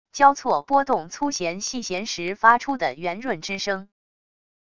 交错拨动粗弦细弦时发出的圆润之声wav音频